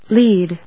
/léd(米国英語), led(英国英語)/